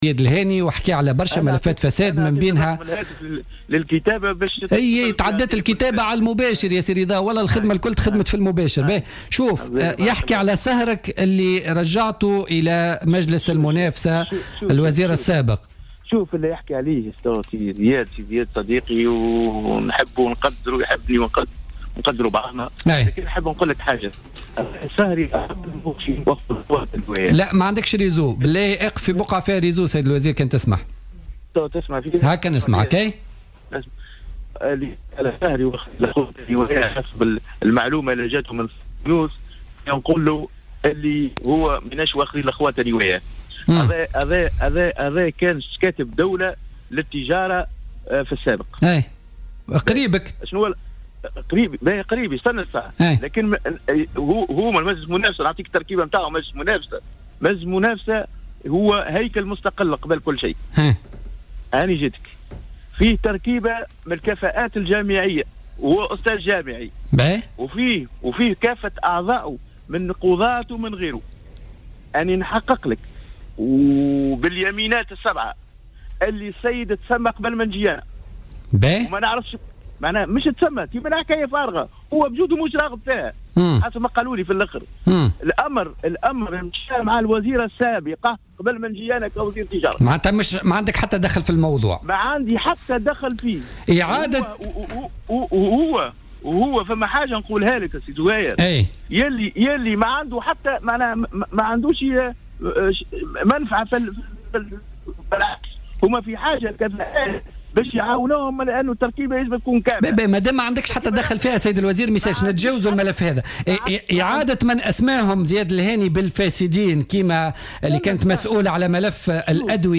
تعهّد وزير التجارة رضا الأحول اليوم في مداخلة له في برنامج بوليتيكا بفتح بحث...